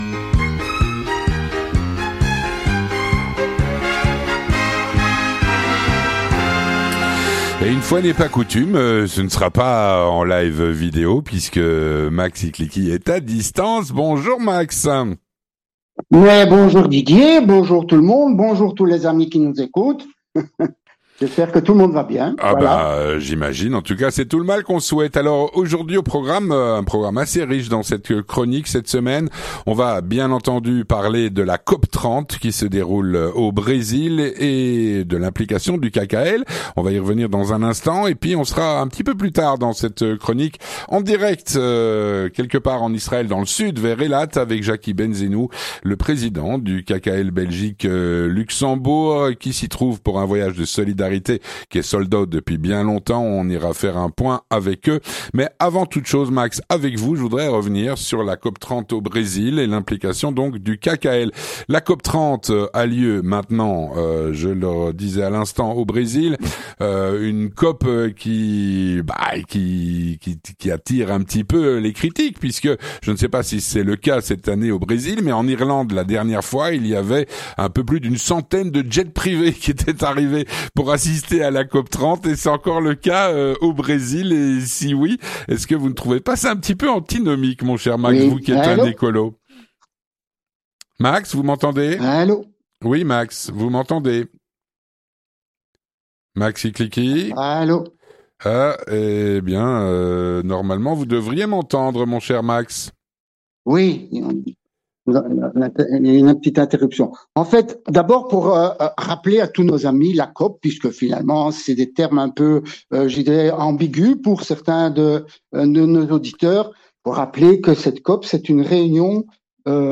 On parle de la Cop 30 au Brésil, de l'implication du KK et nous sommes en direct depuis le Bus du KKL qui effectue son voyade de solidarité dans le Sud d'Israël.